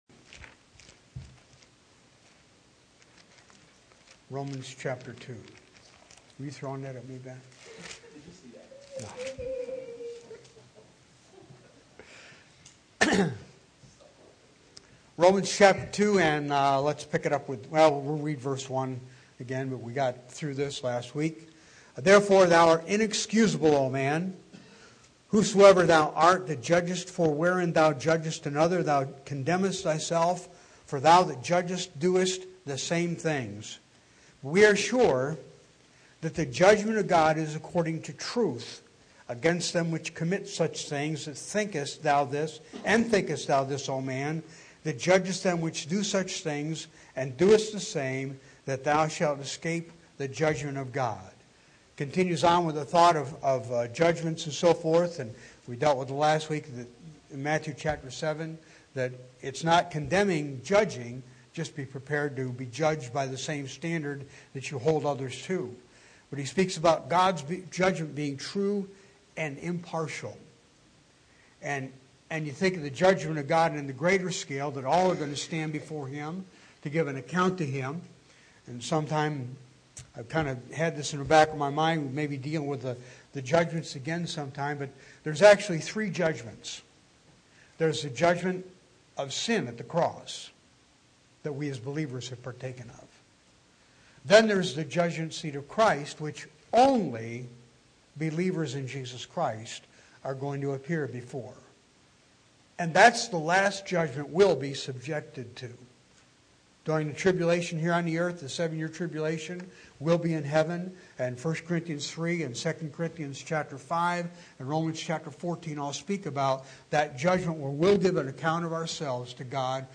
Romans 2:3 Service Type: Bible Study Bible Text